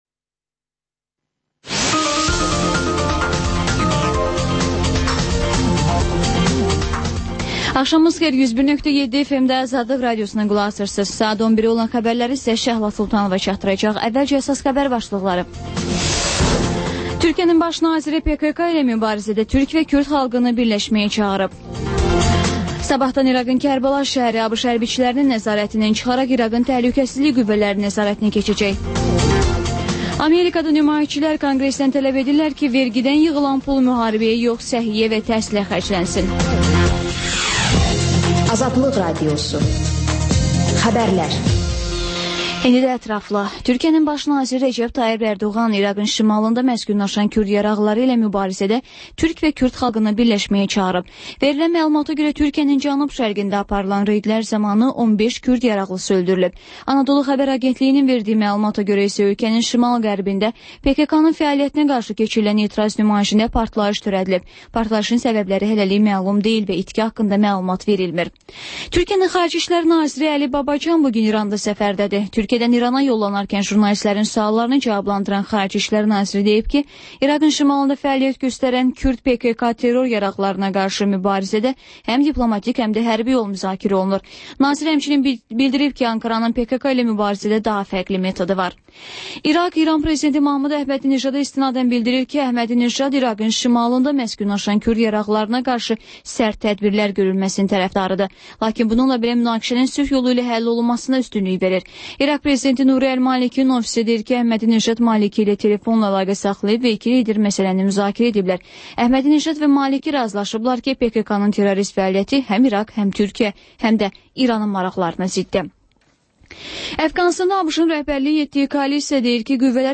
Xəbərlər, İZ: Mədəniyyət proqramı və TANINMIŞLAR verilişi: Ölkənin tanınmış simalarıyla söhbət